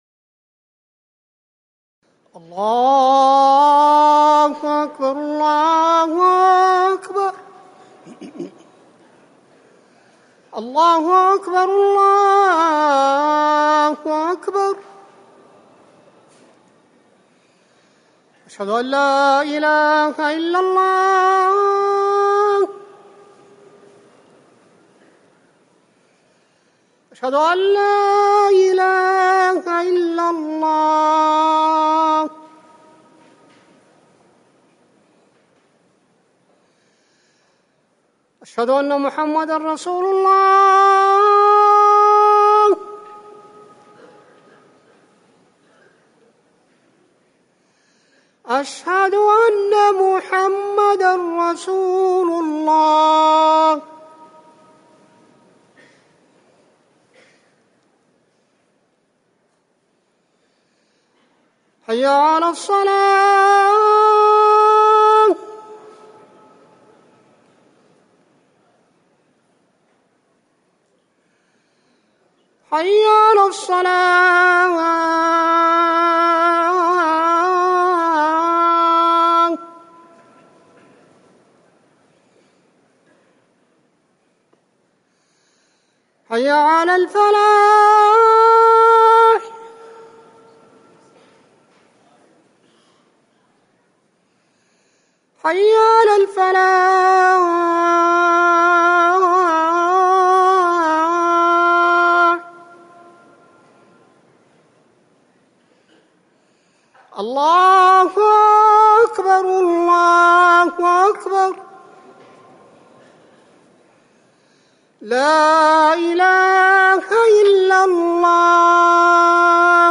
أذان المغرب - الموقع الرسمي لرئاسة الشؤون الدينية بالمسجد النبوي والمسجد الحرام
تاريخ النشر ١٦ محرم ١٤٤١ هـ المكان: المسجد النبوي الشيخ